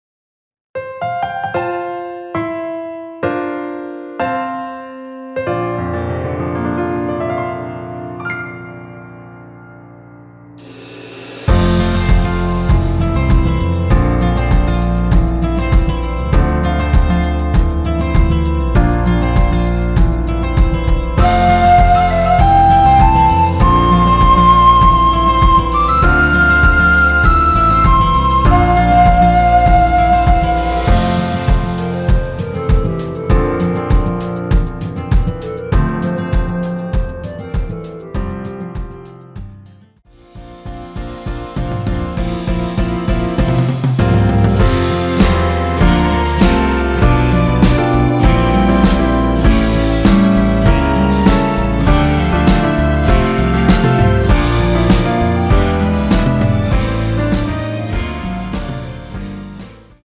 원키에서(-3)내린 멜로디 포함된 MR입니다.
앞부분30초, 뒷부분30초씩 편집해서 올려 드리고 있습니다.
중간에 음이 끈어지고 다시 나오는 이유는